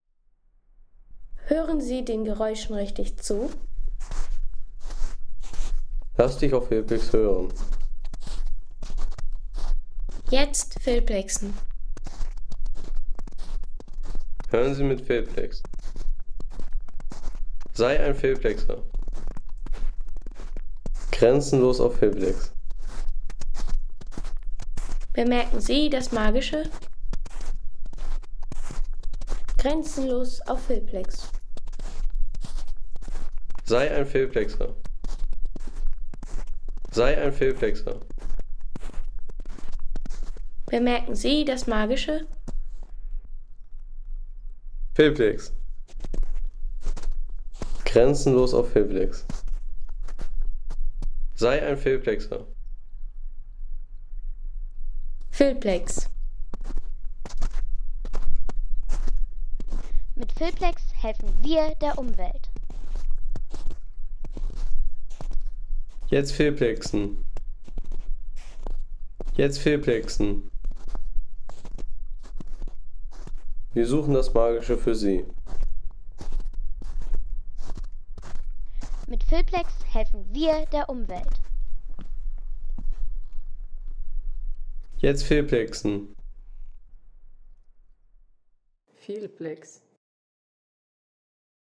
Schritte auf schmelzendem Schnee
Natur - Schnee